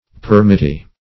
Permittee \Per`mit*tee"\, n. One to whom a permission or permit is given.